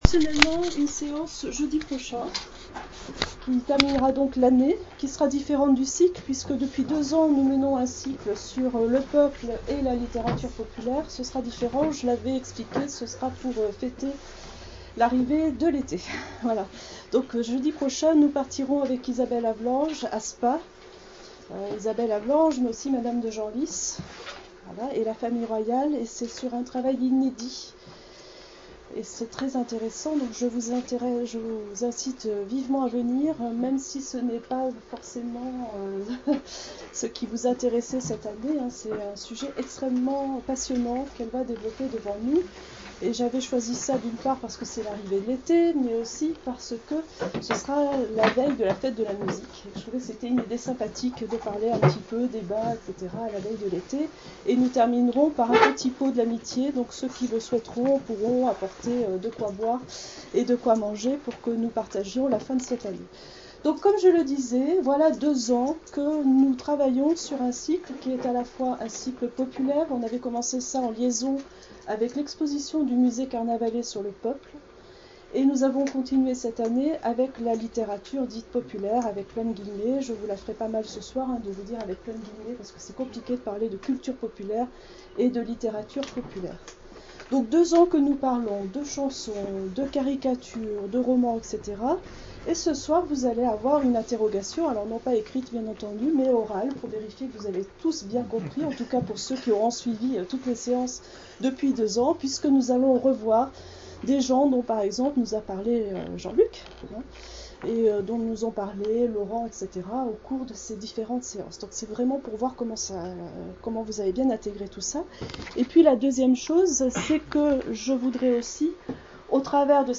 Soirée de lecture de juin 2013